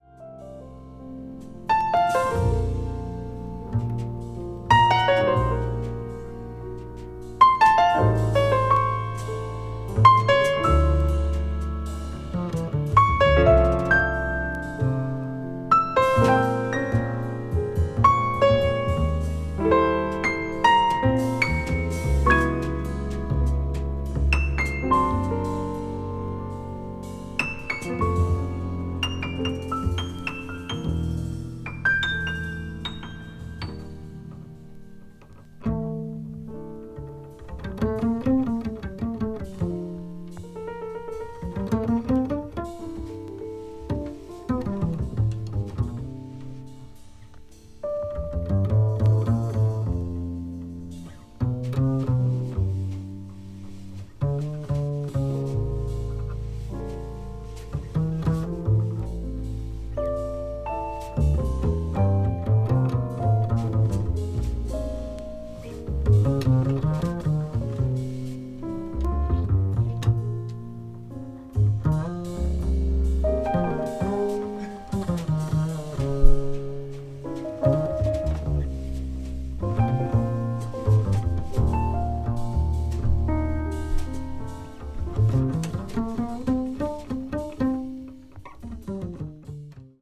contemporary jazz   deep jazz   modal jazz   spritual jazz